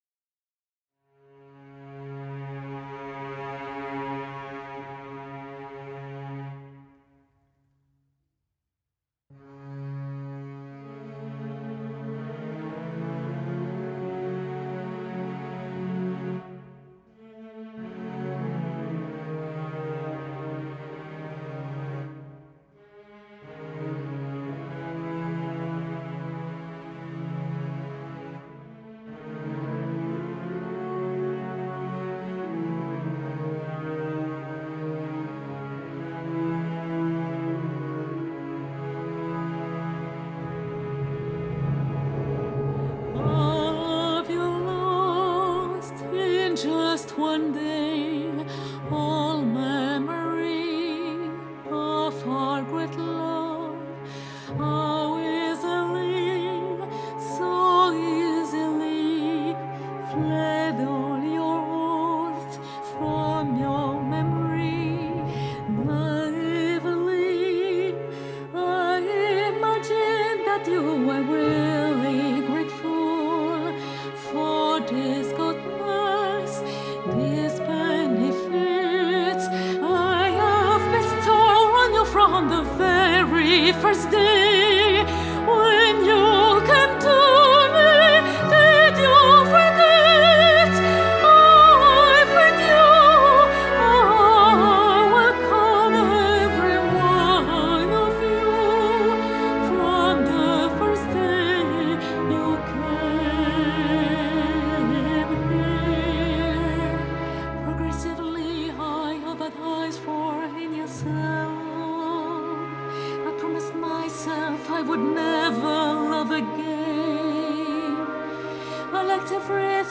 Dido’s response (song 27) is both desperate and scathing.
mezzo-soprano